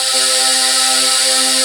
SYNTHETIC.wav